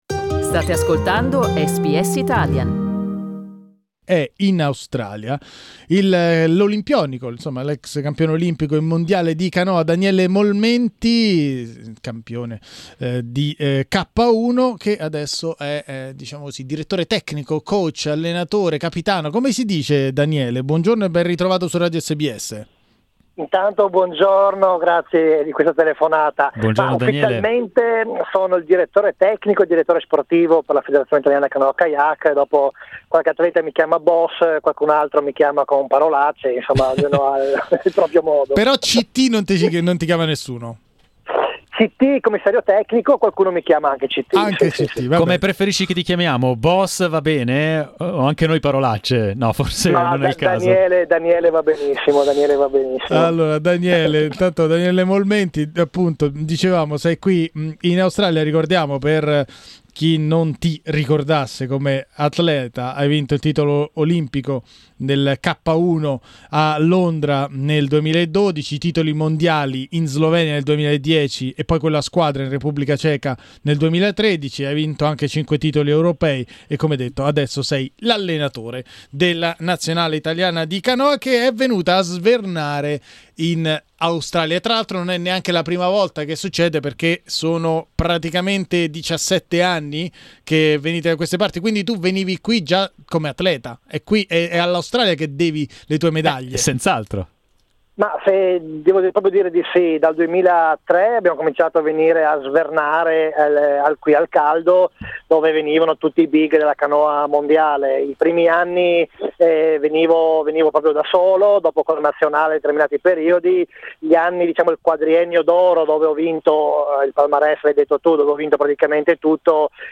Ne parliamo con Daniele Molmenti, olimpionico di K1 a Londra 2012 e adesso direttore tecnico della nazionale.